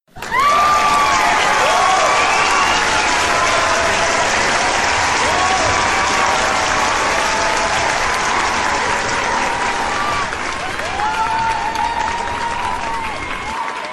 tebrikler_alkis2.mp3